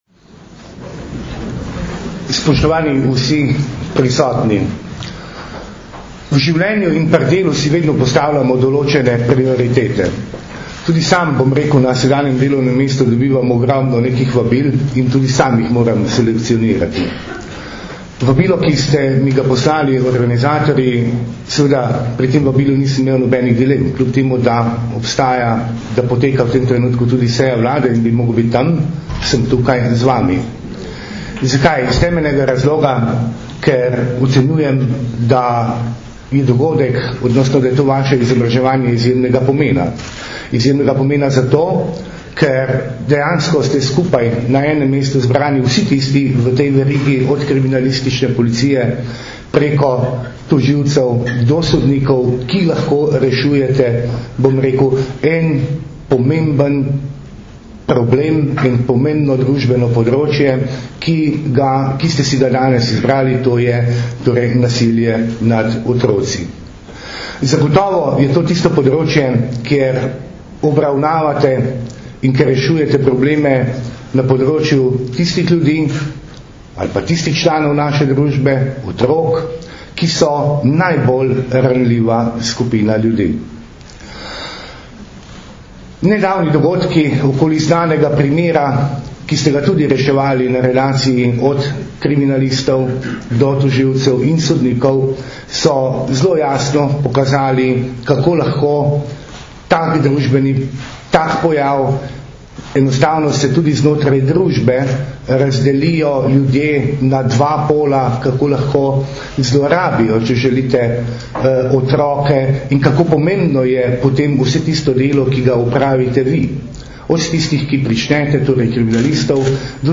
Na Brdu pri Kranju se je danes, 12. aprila 2012, začel dvodnevni strokovni posvet "Nasilje nad otroki – že razumemo?", ki ga letos že enajstič organizirata Generalna policijska uprava in Društvo državnih tožilcev Slovenije v sodelovanju s Centrom za izobraževanje v pravosodju.
Zvočni posnetek nagovora ministra za notranje zadeva dr. Vinka Gorenaka (mp3)